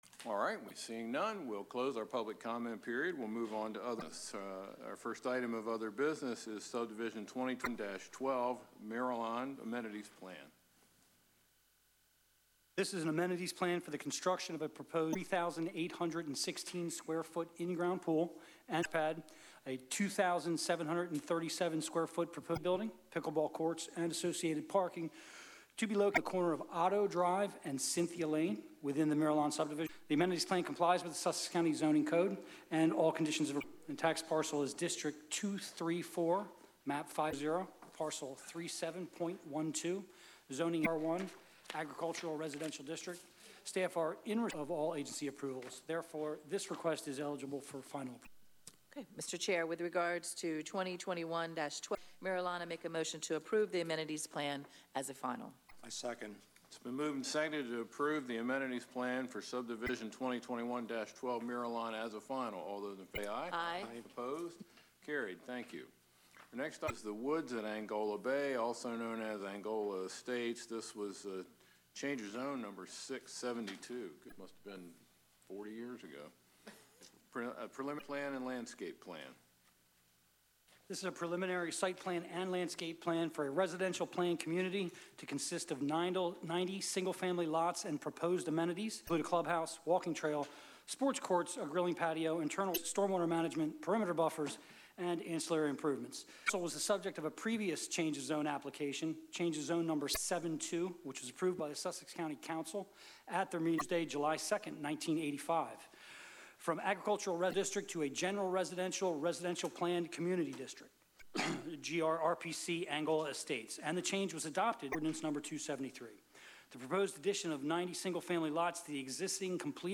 Planning & Zoning Meeting
Council Chambers, Sussex County Administrative Office Building, 2 The Circle, Georgetown